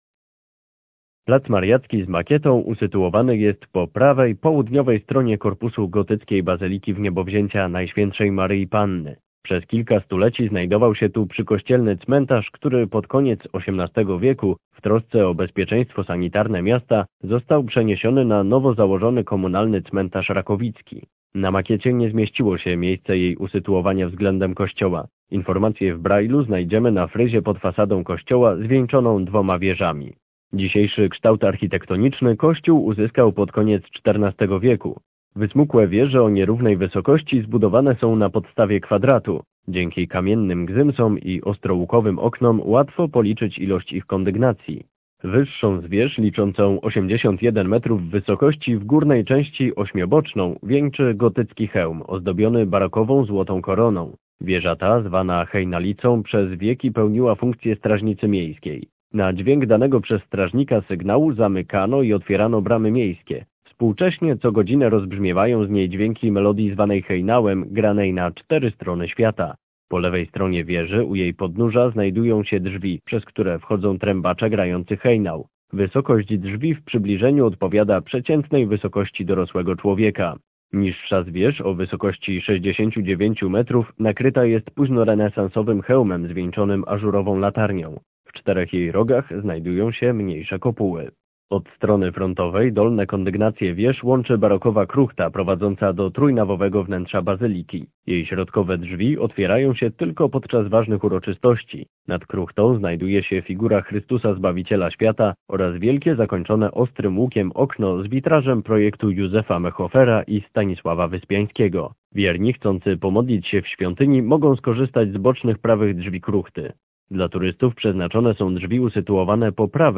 Tekst do odsłuchania (mp3)